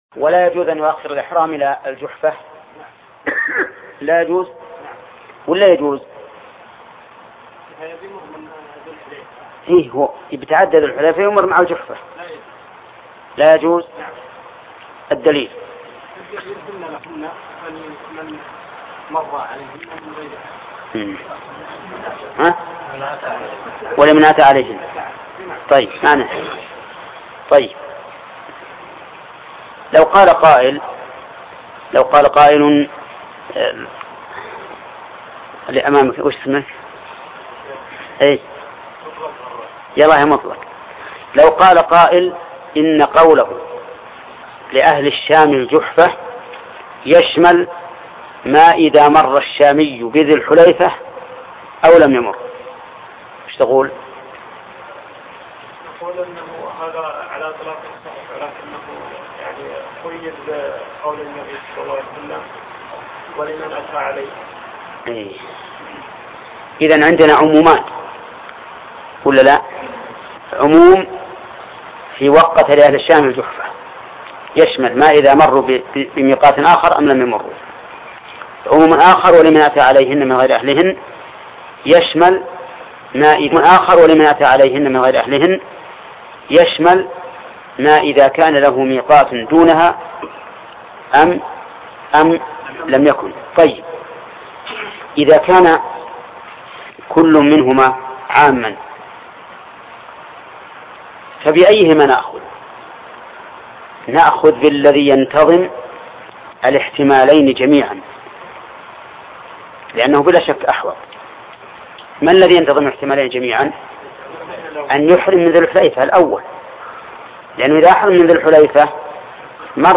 بلوغ المرام من أدلة الأحكام شرح الشيخ محمد بن صالح العثيمين الدرس 121